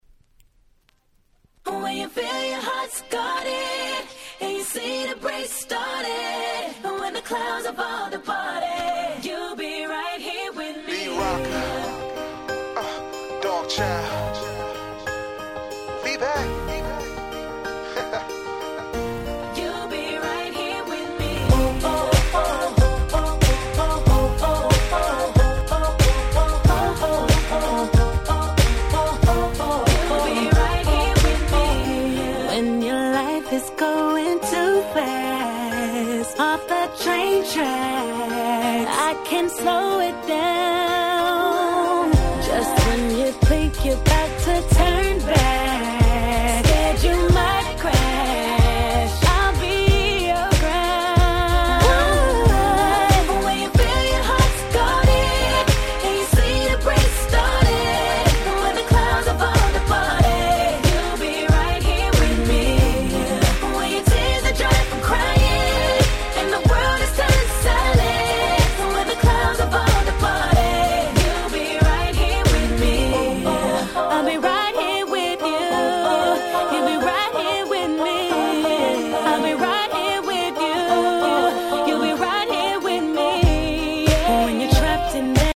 08' Super Hit R&B !!